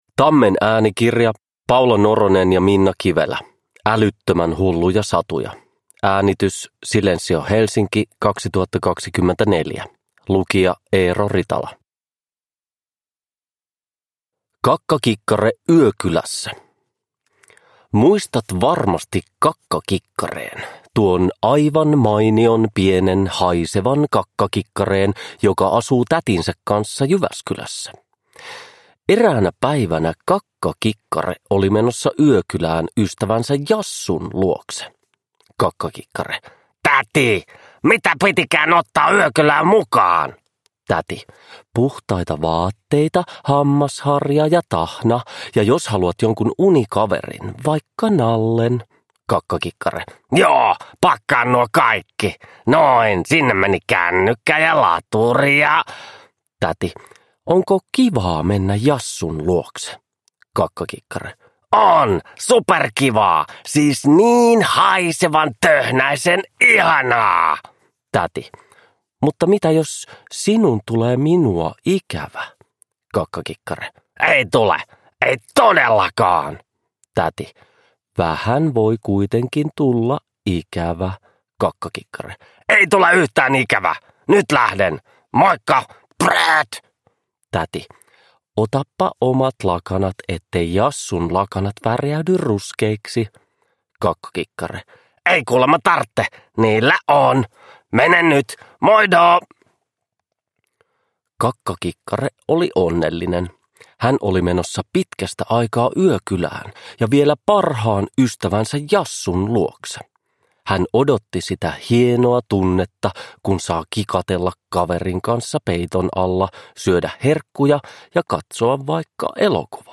Uppläsare: Eero Ritala